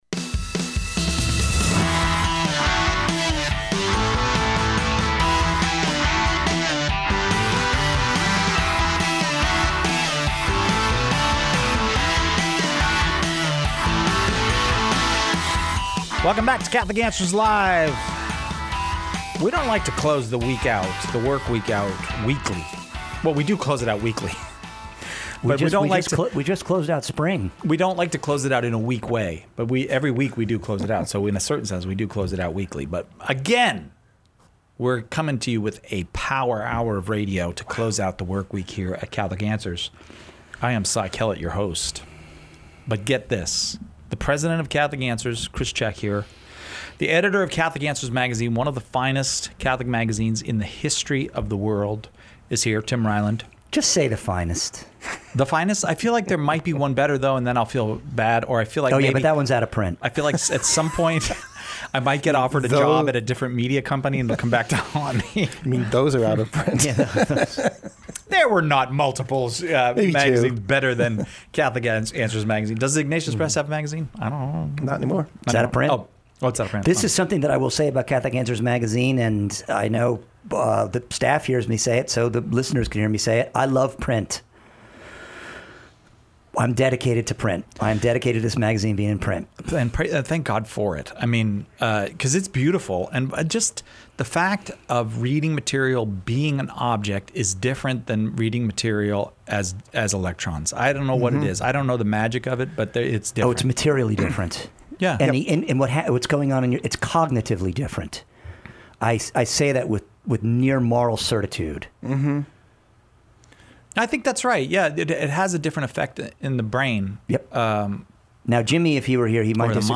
Catholic Answers Live Panel Show